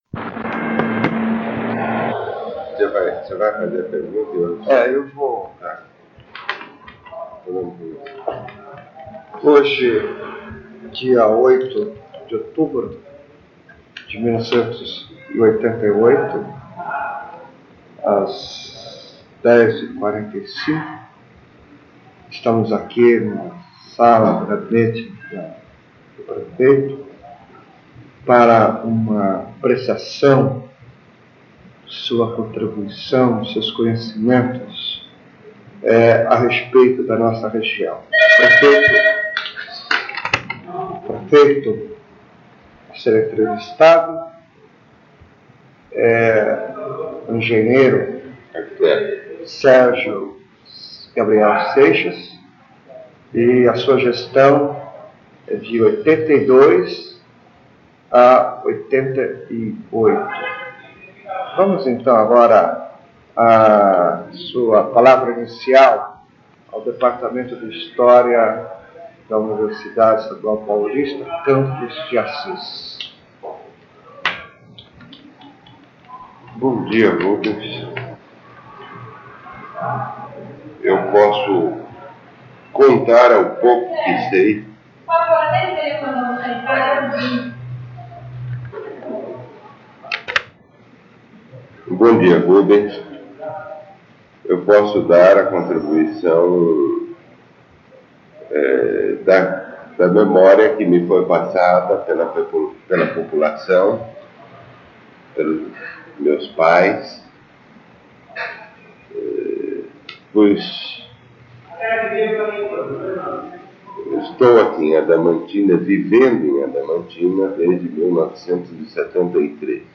Entrevista com Sérgio Gabriel Seixas – Arquiteto e ex-prefeito de Adamantina
*Recomendado ouvir utilizando fones de ouvido.